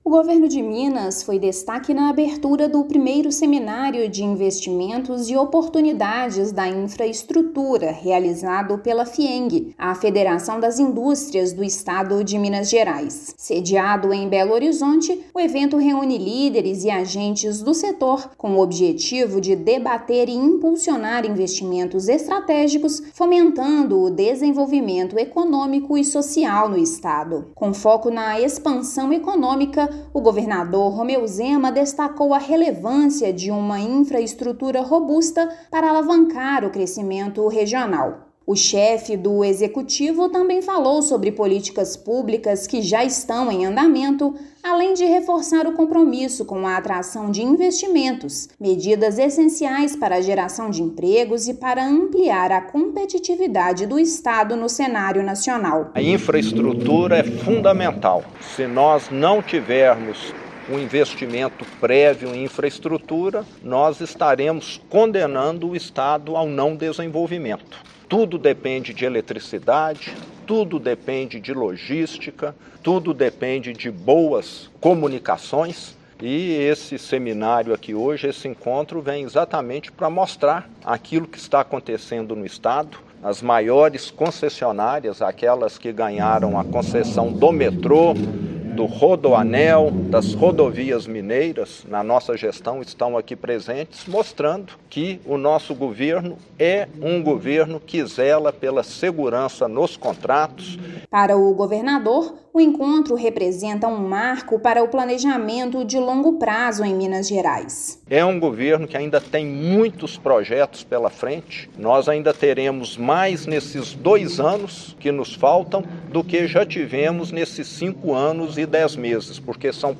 Promovido pela Fiemg na capital mineira, evento reúne representantes dos setores público e privado para debater avanços. Ouça matéria de rádio.